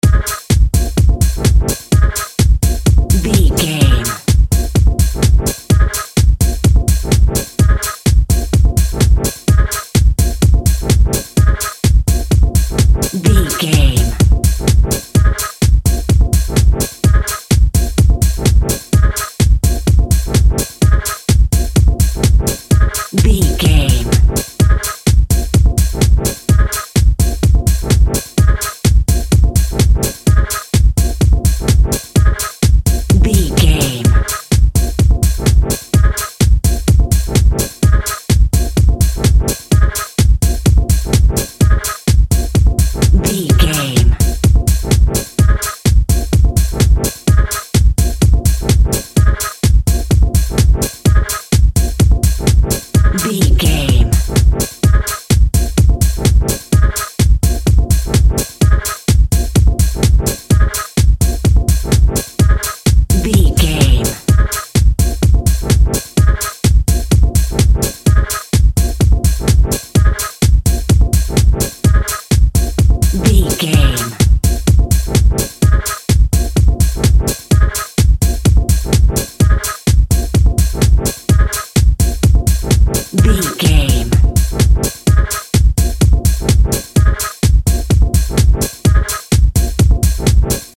Epic / Action
Fast paced
Atonal
A♭
intense
futuristic
energetic
driving
dark
synthesiser
drum machine
electro house
synth lead
synth bass